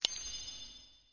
snd_ui_LevelUP.wav